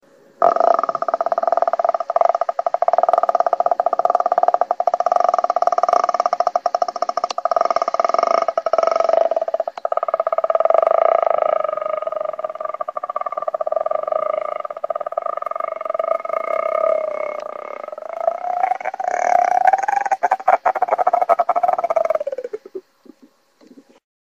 the-grudge-sound_14183.mp3